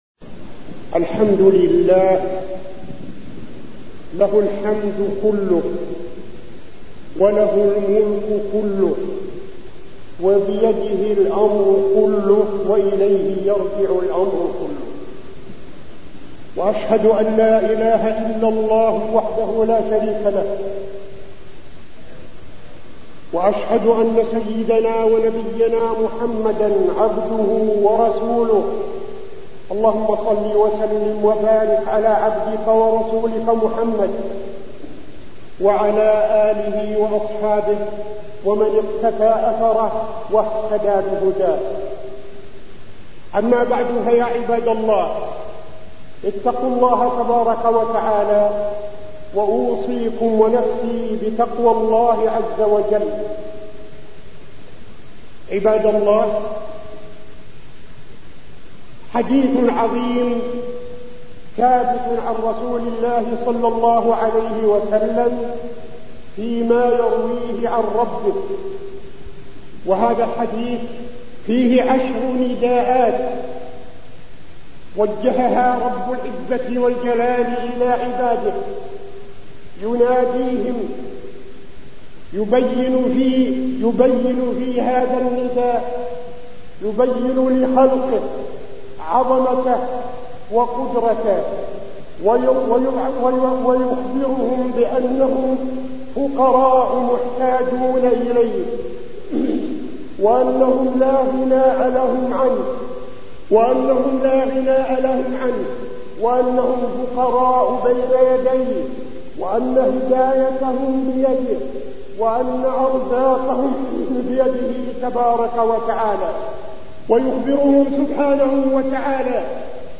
تاريخ النشر ٢٩ ربيع الأول ١٤٠٨ هـ المكان: المسجد النبوي الشيخ: فضيلة الشيخ عبدالعزيز بن صالح فضيلة الشيخ عبدالعزيز بن صالح يا عبادي إني حرمت الظلم على نفسي